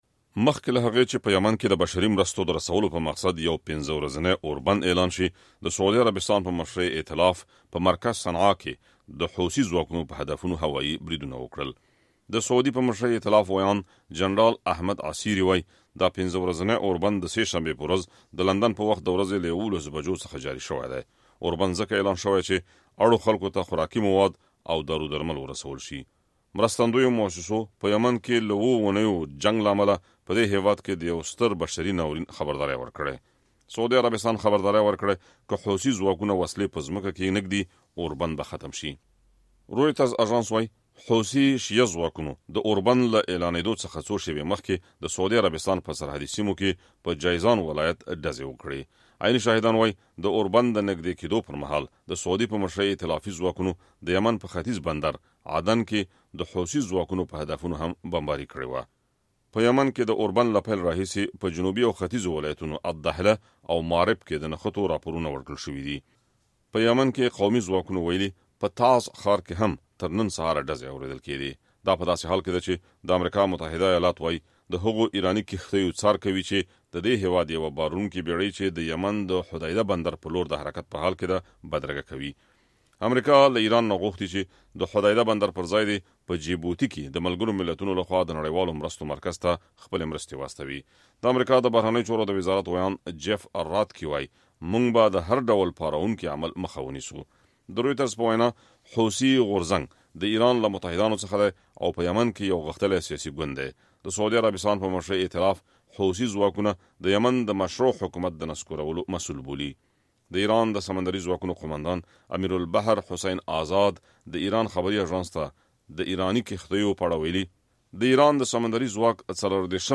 راپورونه